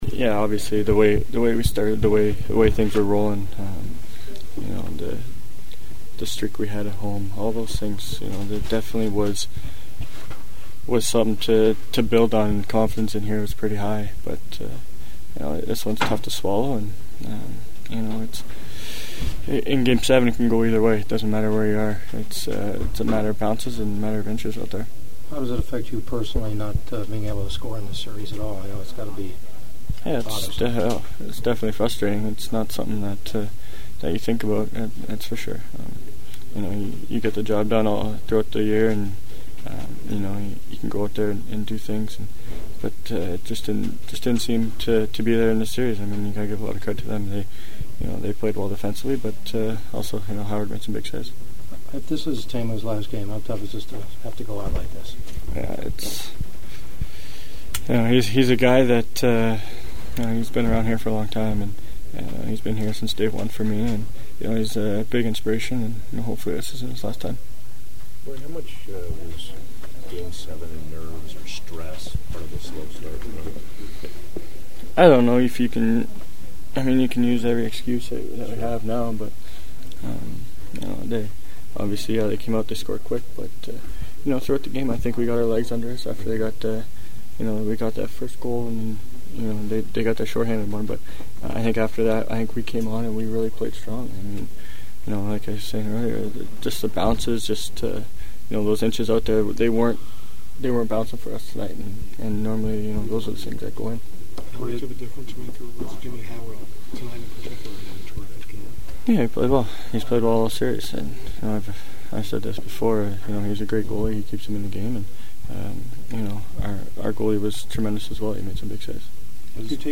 The Ducks had an NHL best 15 come from behind wins during the regular season but their magic also ran out something that was one of my themes of questioning in the postgame locker room.
Ducks right winger Corey Perry who never scored a goal in the entire series…and it was hardly fun having to ask him about that!: